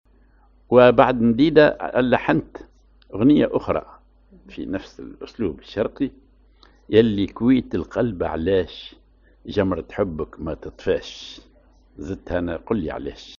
Maqam ar النهوند
Rhythm ar الوحدة
genre أغنية